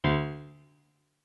MIDI-Synthesizer/Project/Piano/18.ogg at 51c16a17ac42a0203ee77c8c68e83996ce3f6132